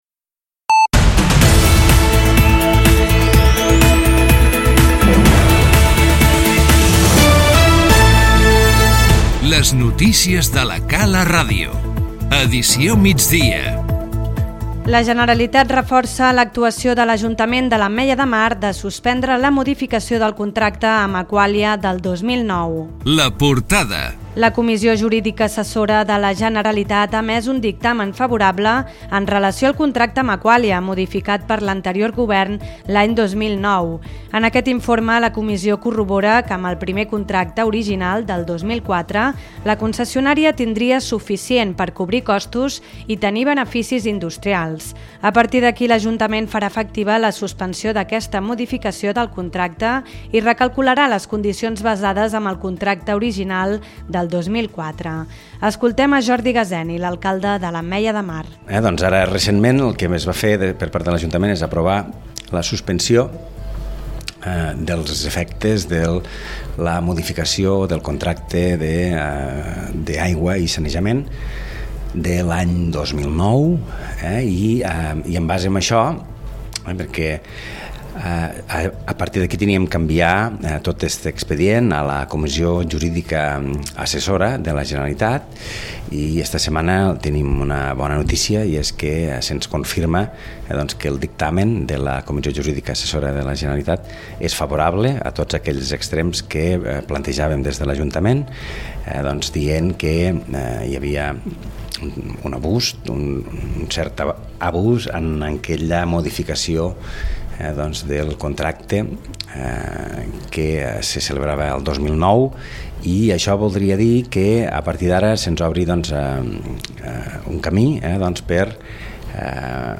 Les notícies 31/07/2018